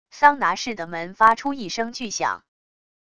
桑拿室的门发出一声巨响wav音频